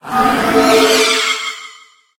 Cri de Lanssorien dans Pokémon HOME.